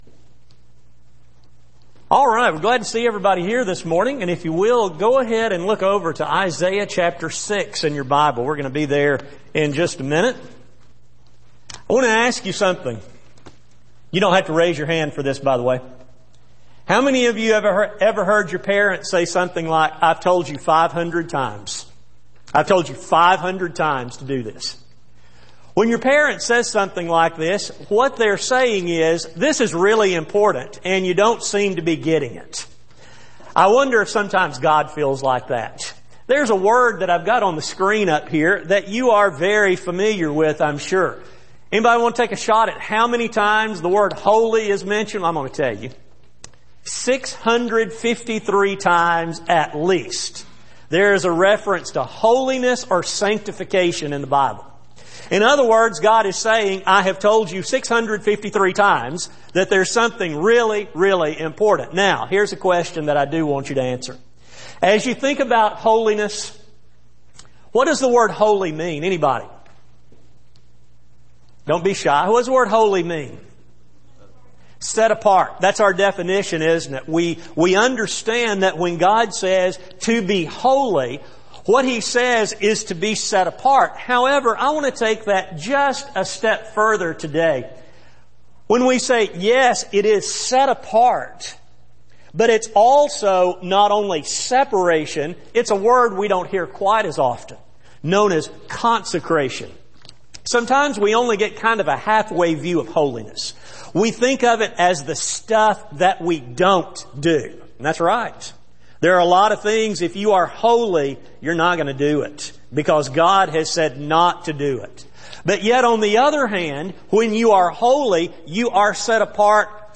Service: Special Event Type: Sermon